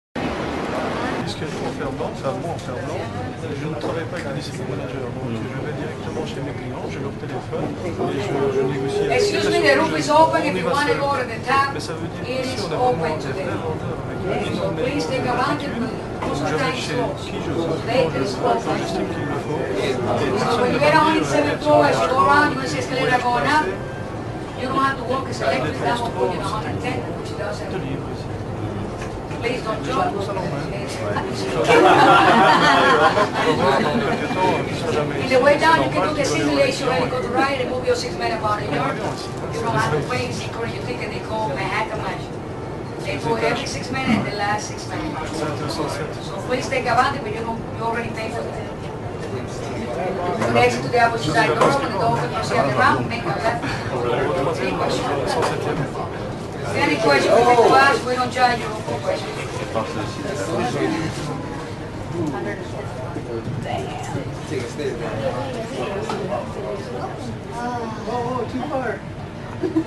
It appears to show someone filming the lobby indicator for the WTC express lift travelling from ground level to the top viewing area with no break.